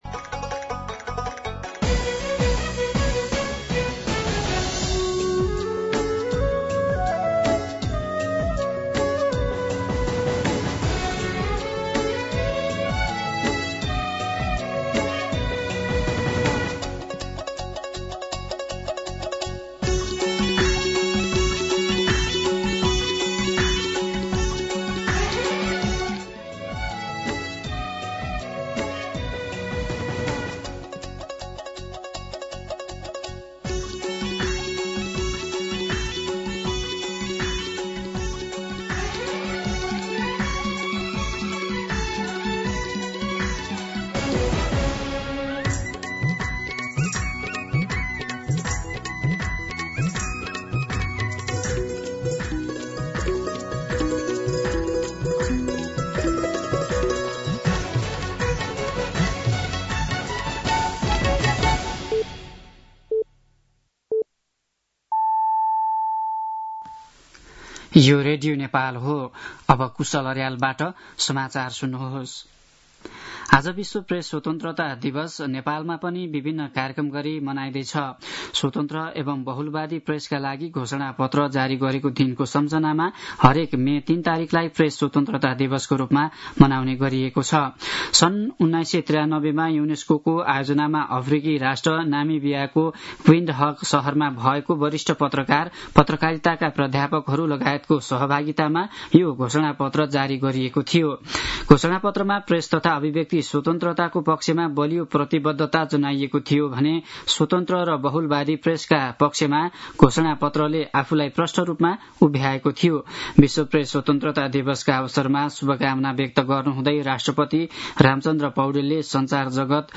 मध्यान्ह १२ बजेको नेपाली समाचार : २० वैशाख , २०८२